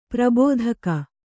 ♪ prabōdhaka